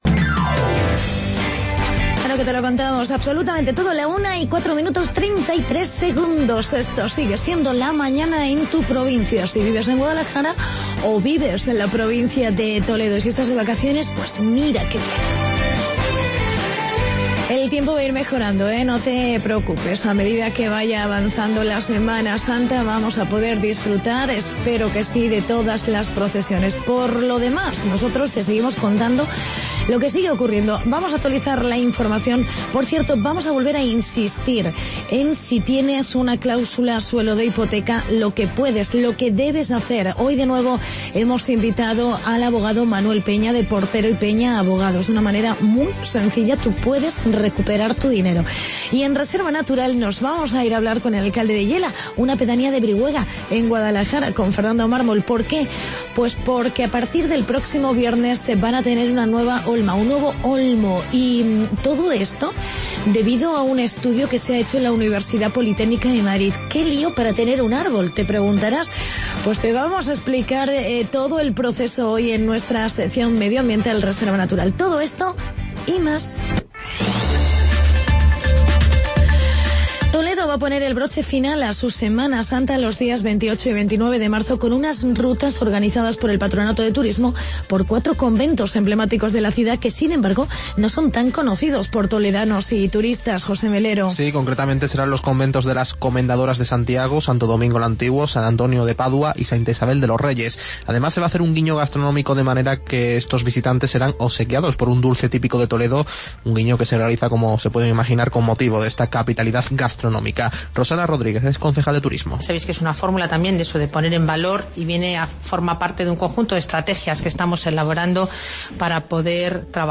Entrevista
en "Reserva Natural" hablamos con el alcalde de Yela, Fernando Mármol.